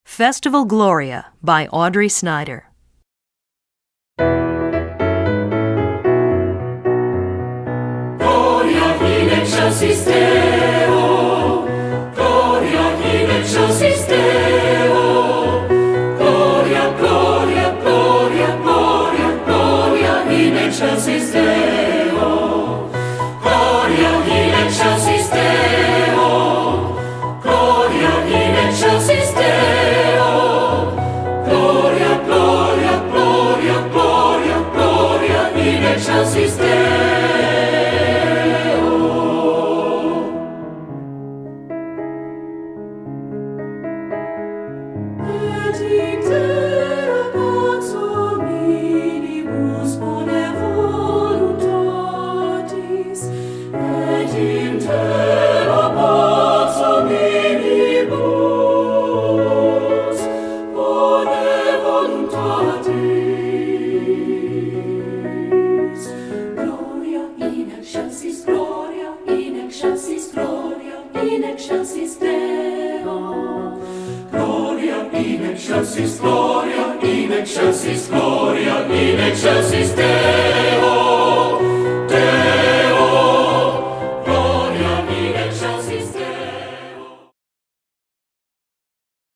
Voicing: SSA